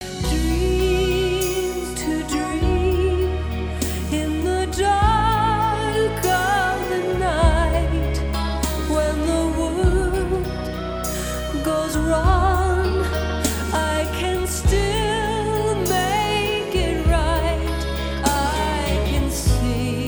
Tukaj sem naredil par fileov za testiranje kvalitete mojega kodeka, mp3-ja in original wave-a (CDja).
Spc Music Format 3.0 44.1kHz Stereo 942KB